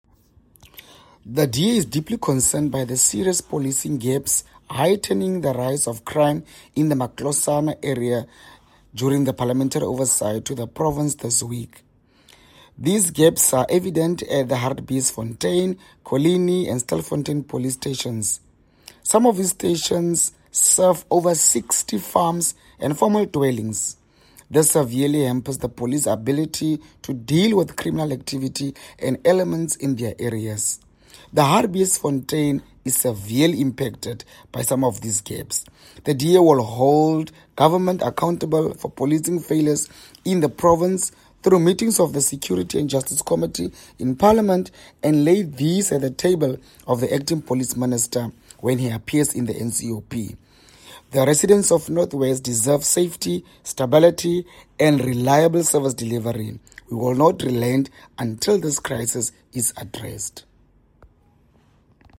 Issued by Ofentse Mokae MP – DA Member of Select Committee on Security and Justice
Afrikaans soundbites as well as video by Ofentse Mokae MP.